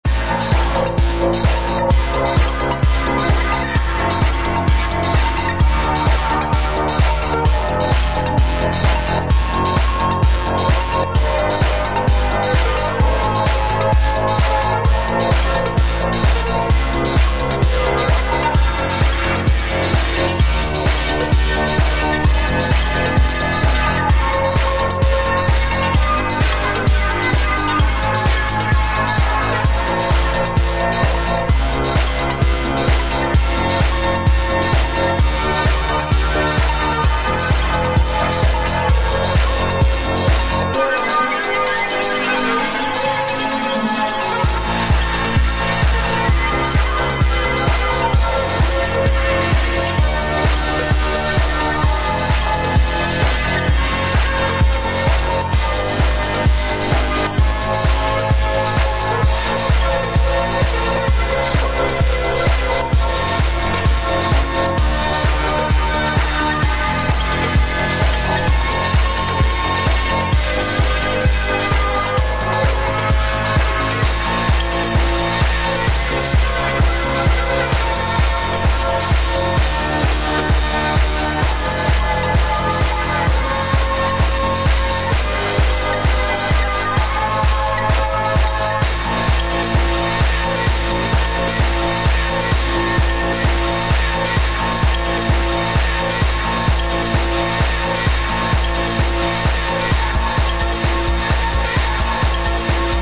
Super loud and rocking nu-school electro/rave track.